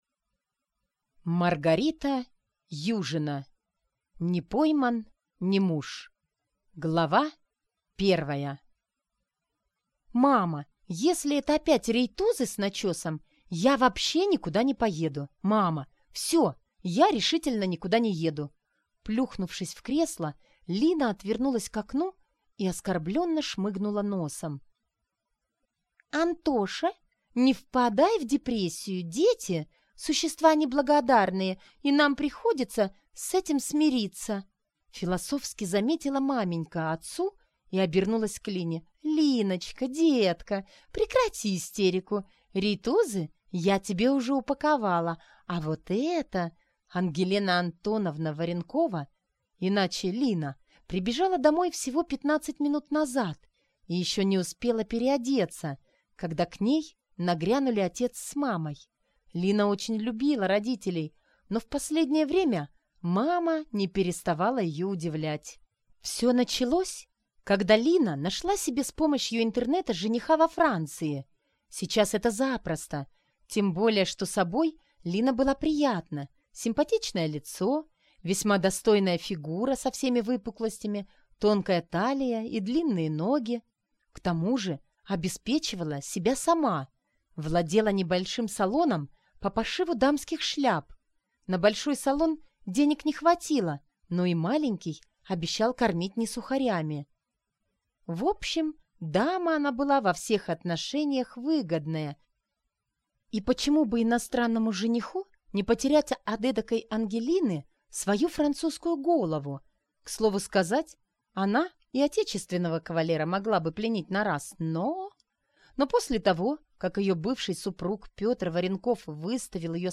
Аудиокнига Не пойман – не муж | Библиотека аудиокниг